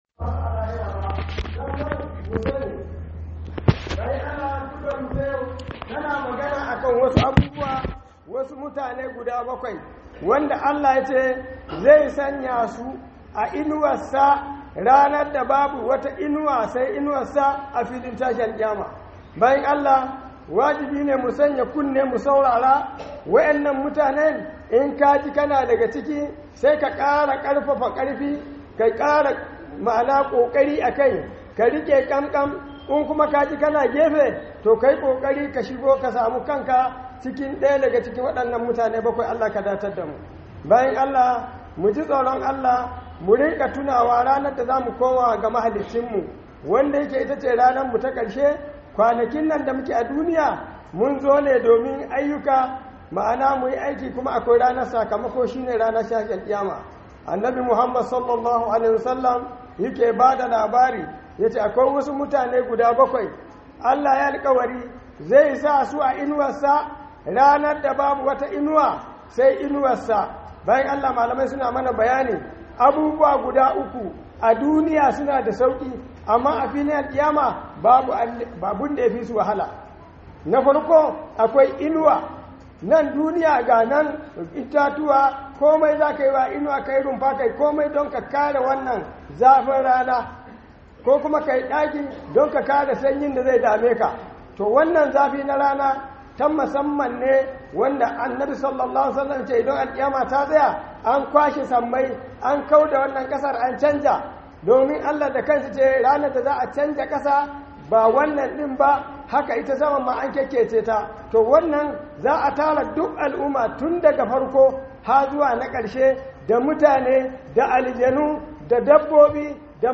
Khudubar Sallar Juma'a by JIBWIS Ningi
Khuduba - mutane bakwai da zasu shiga inuwar Al'arshi